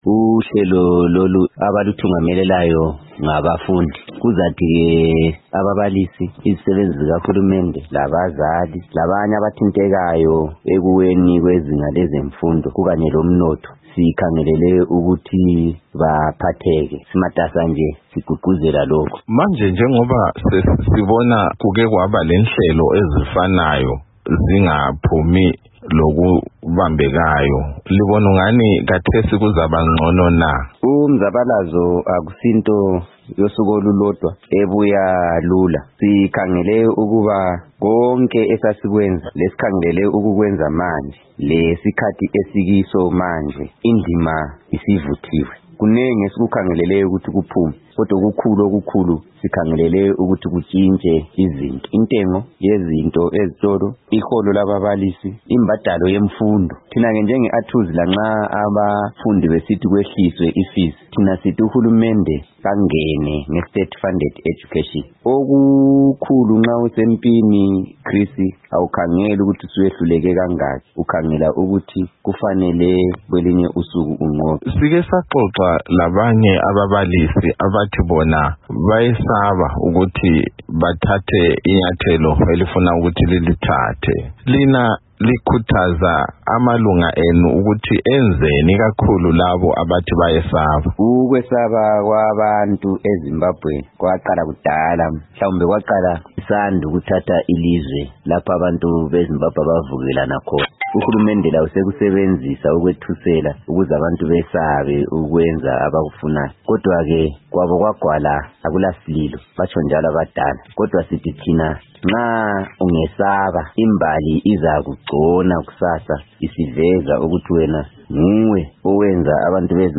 Ingxoxo